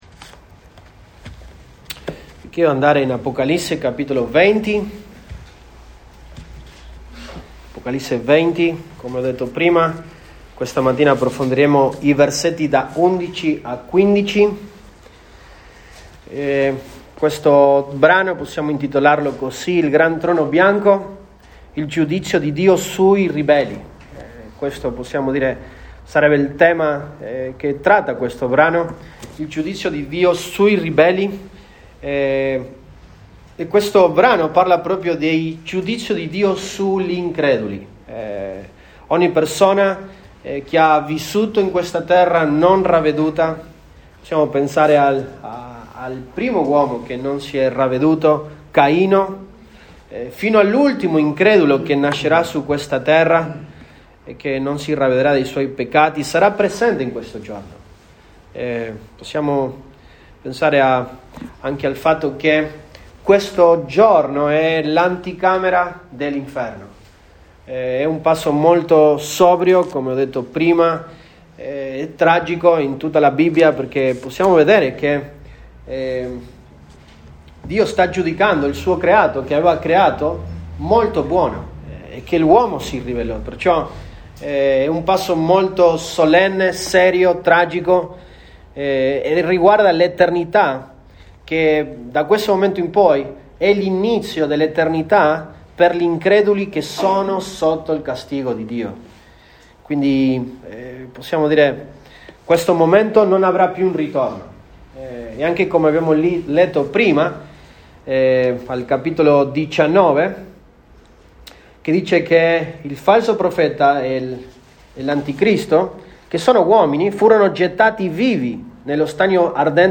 Mar 05, 2023 Il grande trono bianco MP3 Note Sermoni in questa serie Il grande trono bianco.